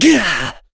Kasel-Vox_Damage_02.wav